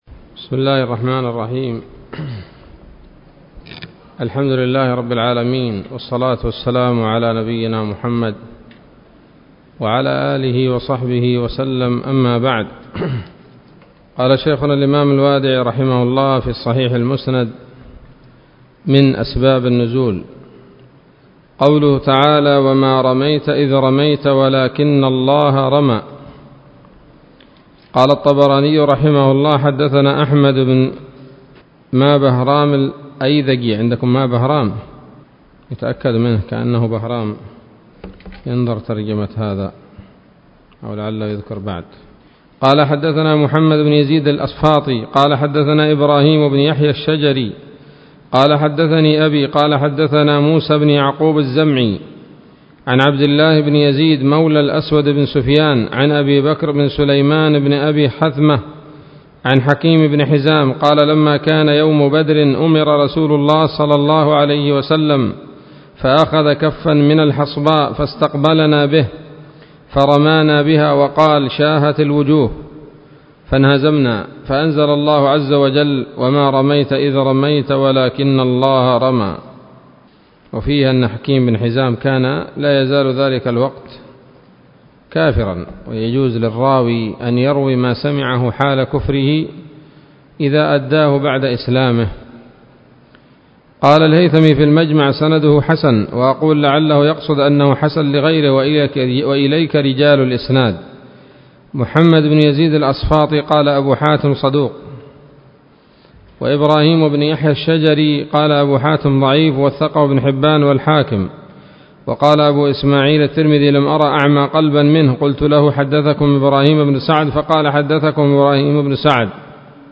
الدرس الأربعون من الصحيح المسند من أسباب النزول